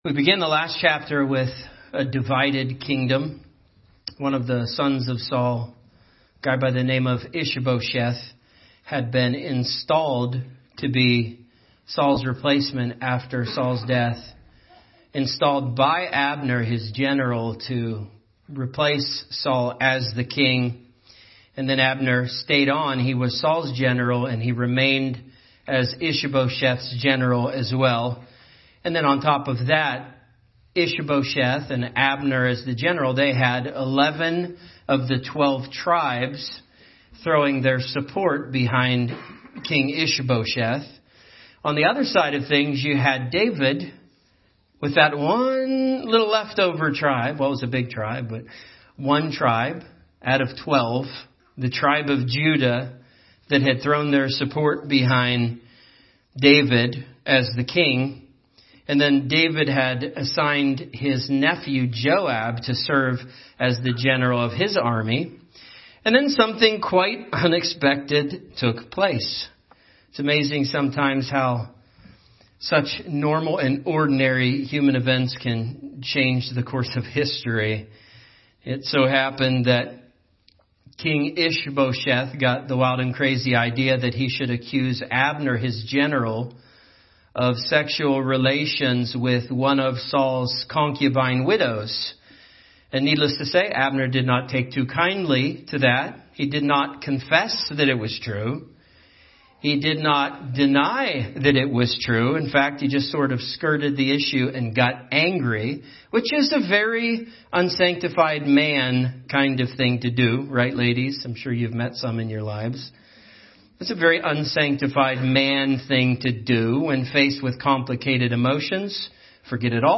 A message from the topics "The Book of 2 Samuel."